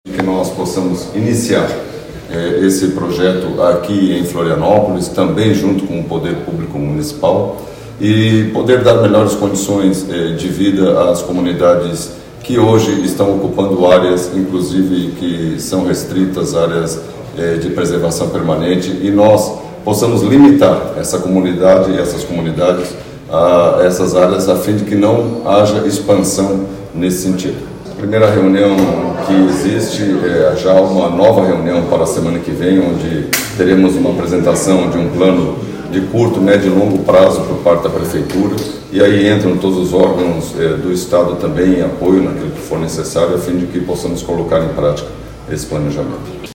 O secretário de Estado da Segurança Pública, Flávio Graff, destacou a importância da ação coordenada entre Estado e município para enfrentar o problema: